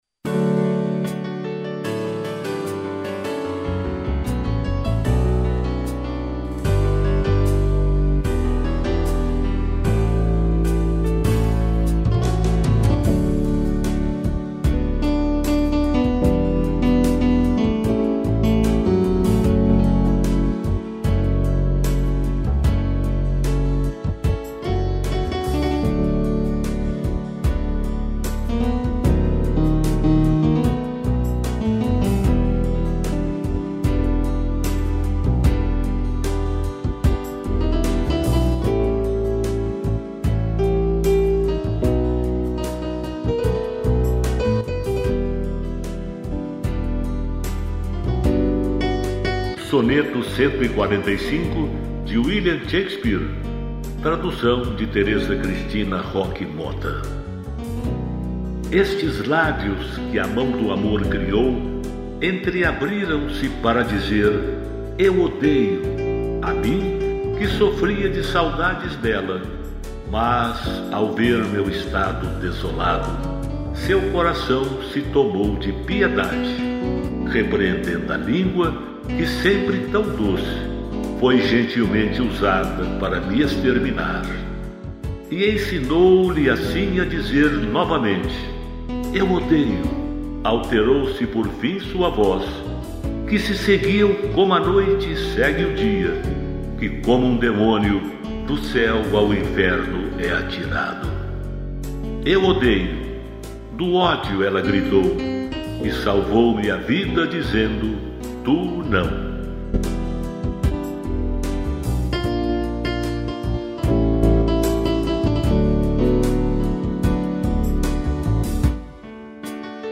piano, tutti e sax